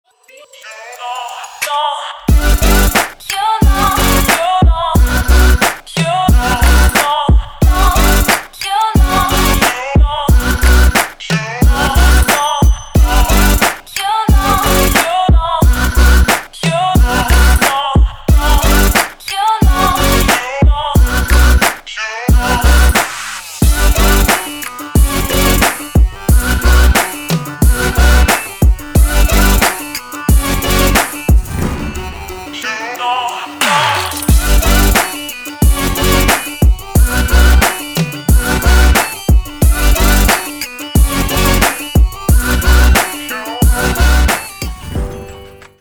Trap
Light Trap